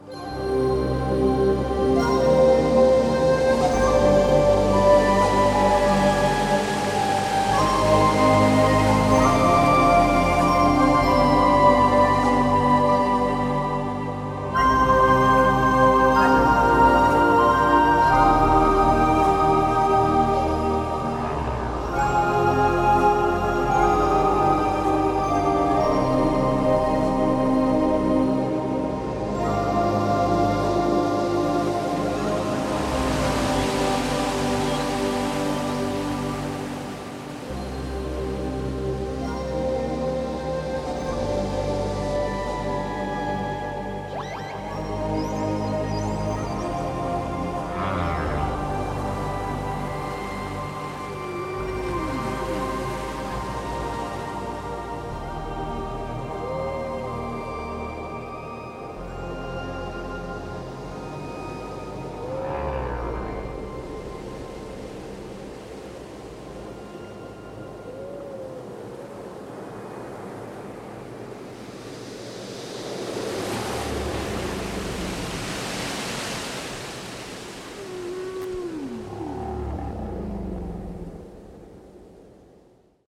heel ontspannend, met nogal wat natuurgeluiden
een samenzang van hobo en walvis...